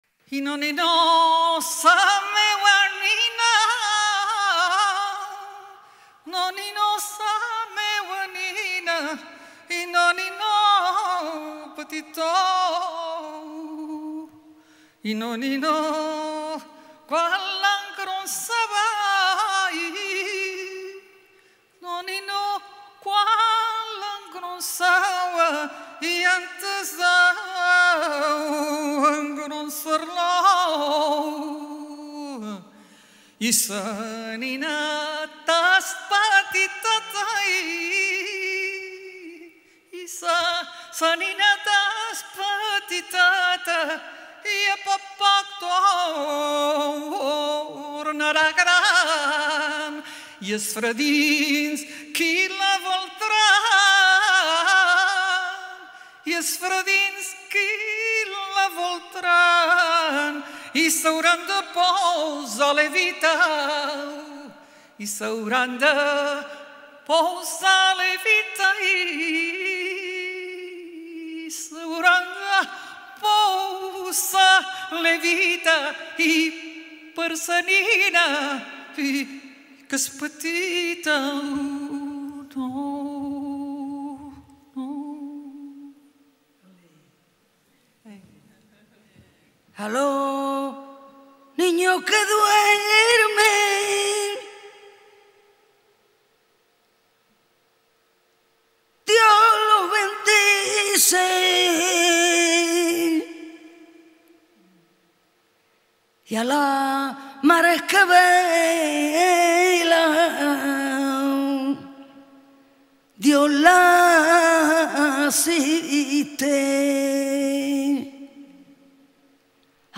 En Directo